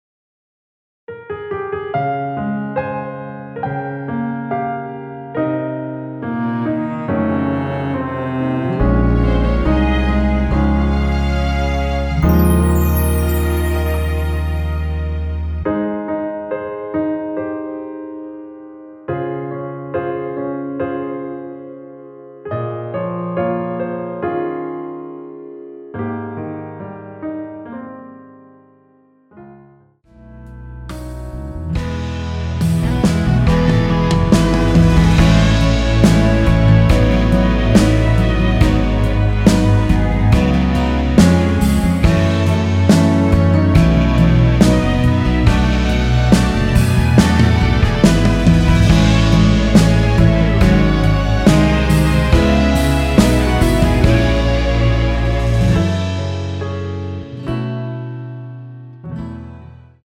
원키에서(-3)내린 MR입니다.
Ab
앨범 | O.S.T
앞부분30초, 뒷부분30초씩 편집해서 올려 드리고 있습니다.
중간에 음이 끈어지고 다시 나오는 이유는